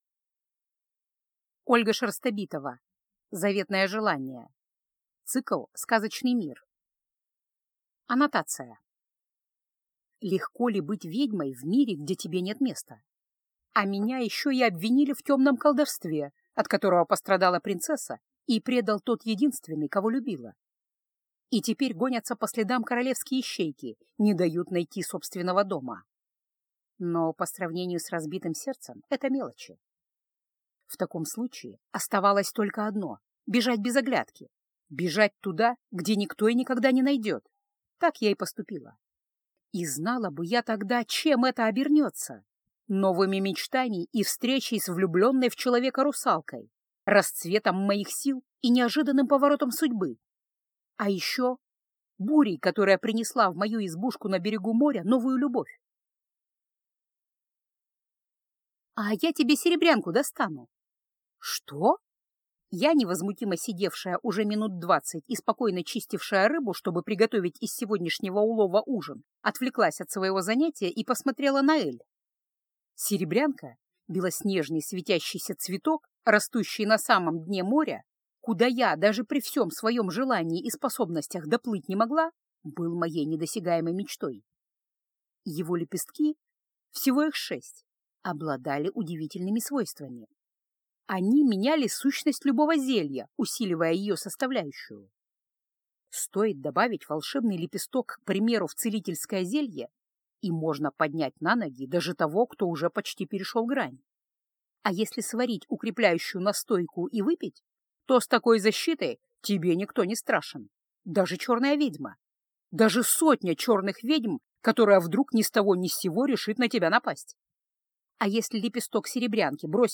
Аудиокнига Заветное желание | Библиотека аудиокниг